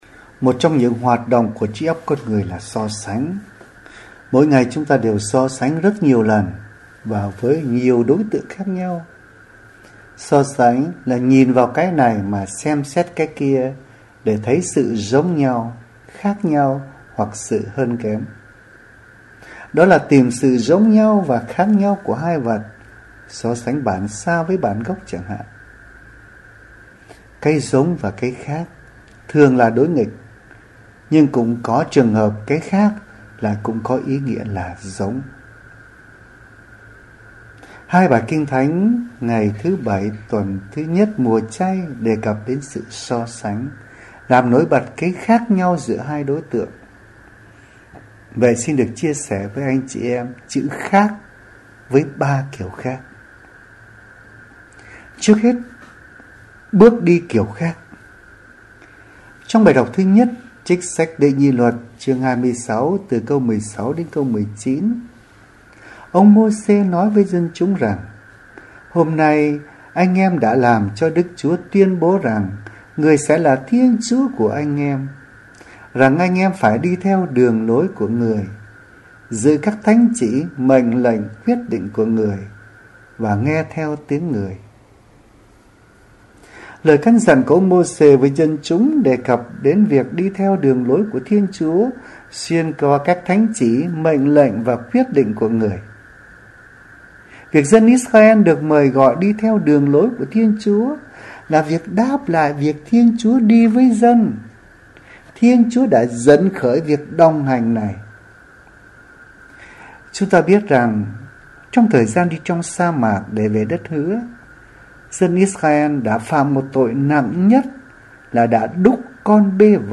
Suy niệm hằng ngày Thứ Bảy, Tuần I Mùa Chay: Khác…